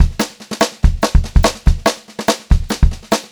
144SPBEAT3-L.wav